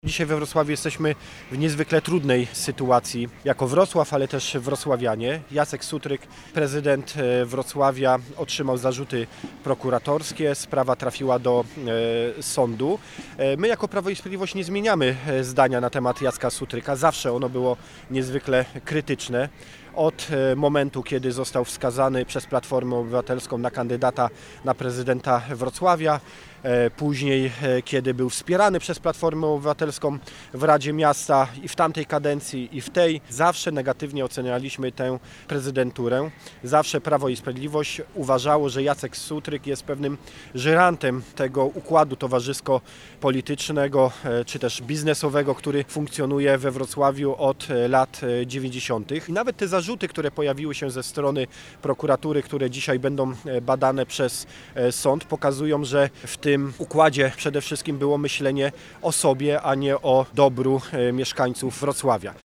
– Oczekuję, abyśmy przedstawili mieszkańcom kandydata obywatelskiego, który przeciwstawi się środowisku biznesowo-polityczno-towarzyskiemu, które rządzi Wrocławiem od lat 90. – zaznacza poseł Paweł Hreniak, prezes wrocławskich struktur PiS.
01_posel-Hreniak.mp3